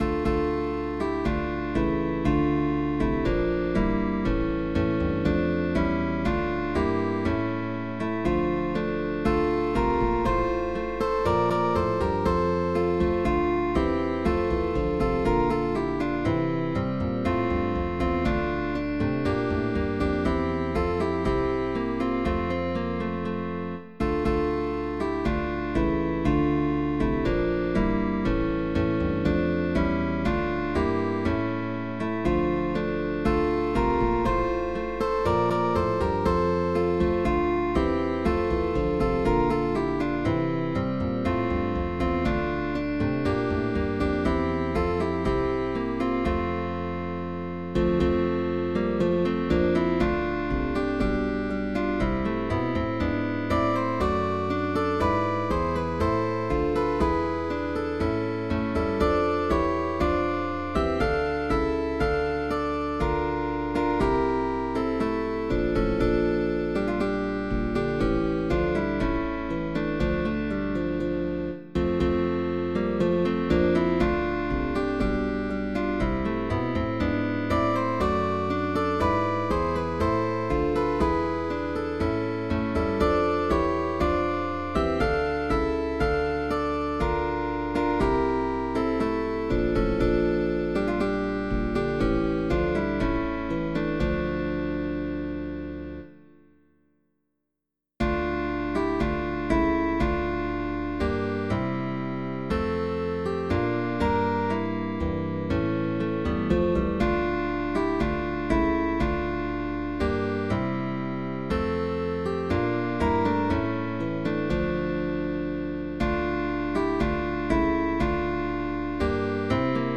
CUARTETO DE GUITARRAS
compositor del barroco alemán.
Guitarra bajo, opcional.